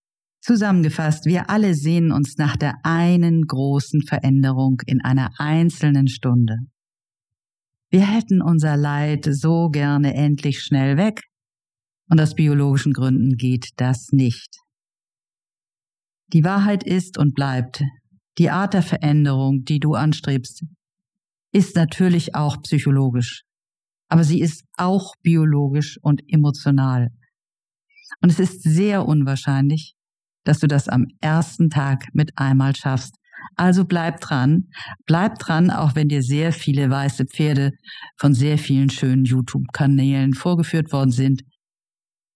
Einführung: Einführungstalk
• Musik: Ja;